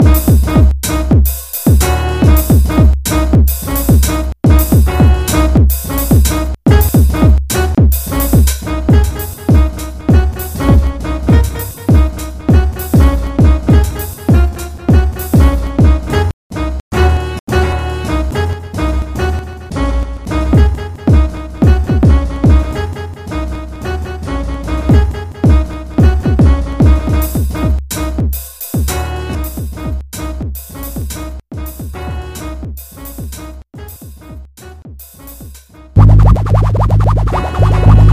Just sort of a groovy hip-hop thing I made with mostly
Kind of cheery for me :)